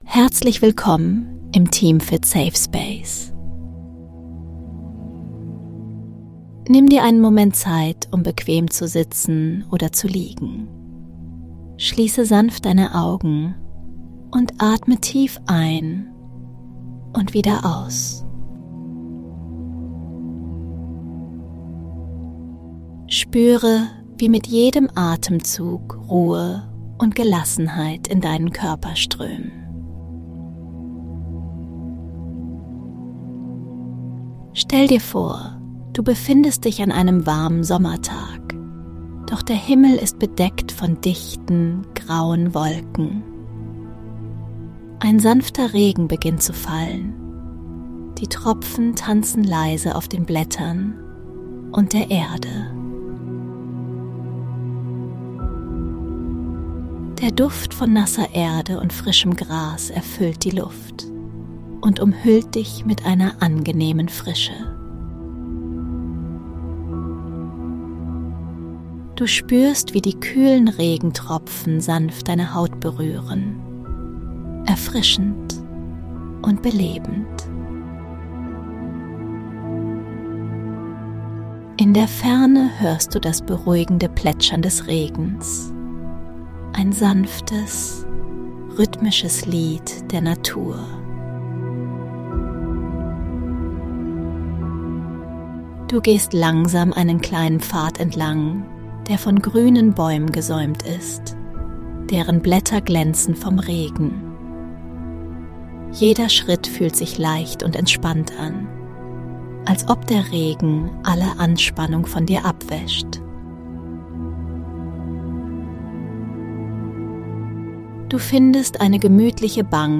Erlebe eine kurze Traumreise in einen beruhigenden Sommerregen.